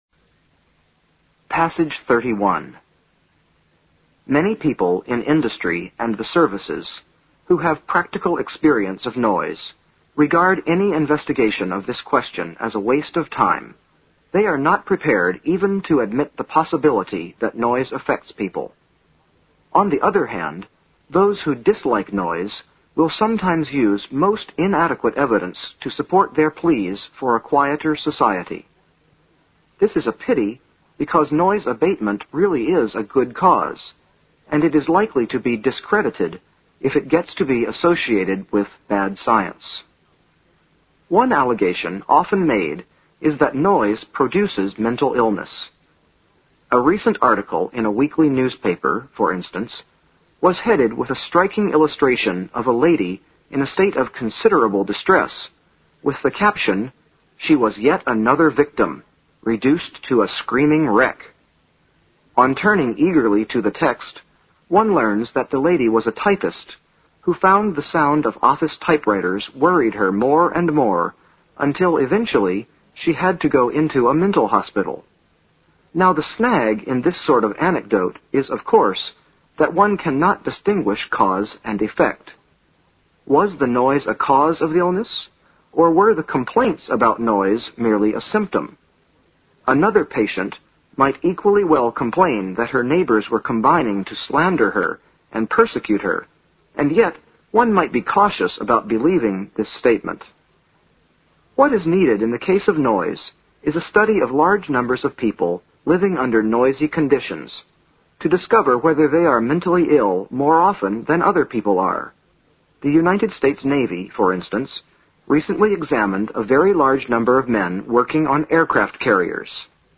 新概念英语85年上外美音版第四册 第31课 听力文件下载—在线英语听力室